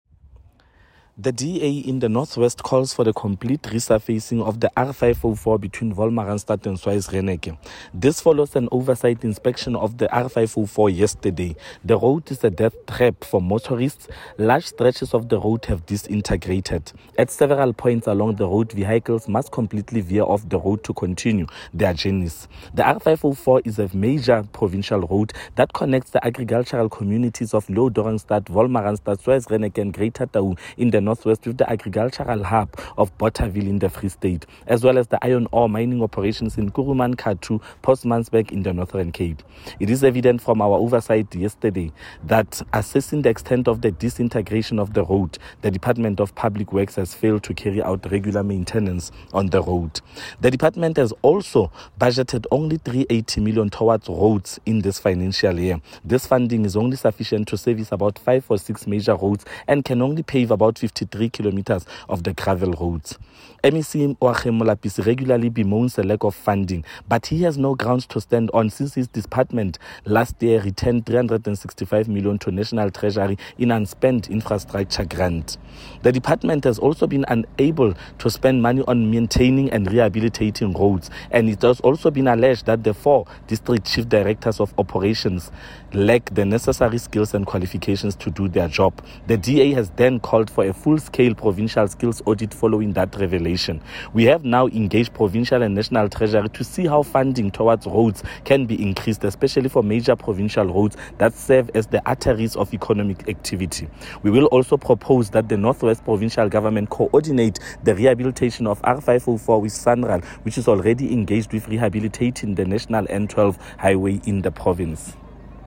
Please find a soundbite in
English by Freddy Sonakile MPL